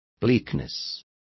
Complete with pronunciation of the translation of bleakness.